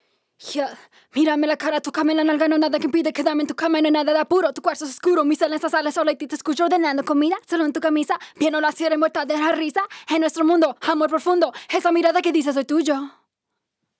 AutoTune-Hard-Tuned-RAW.wav